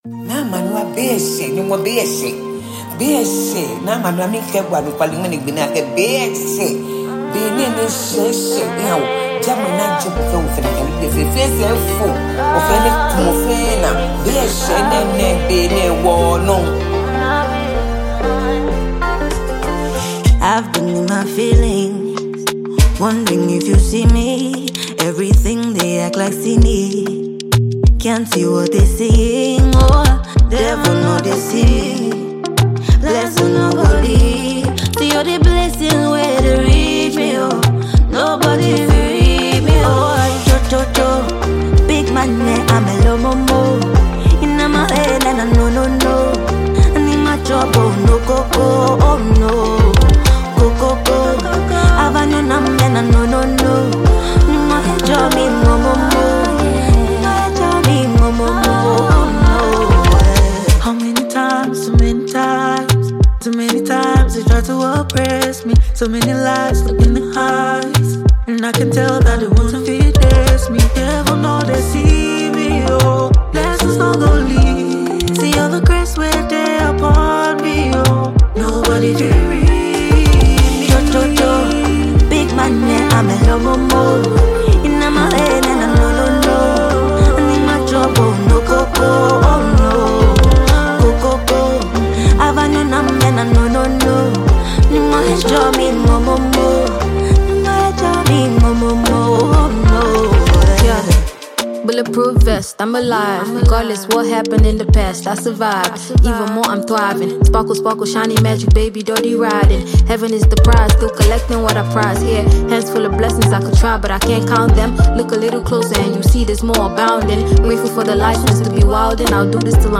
Ghanaian female vocalist and songwriter